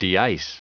Prononciation du mot deice en anglais (fichier audio)
Prononciation du mot : deice